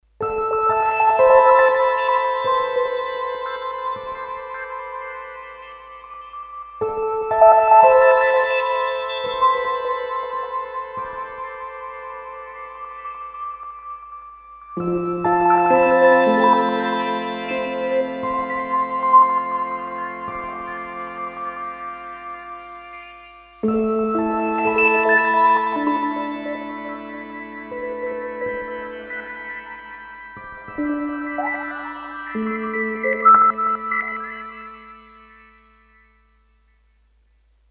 Virtual Analog Synthesizer
M-Audio Venom single patch demos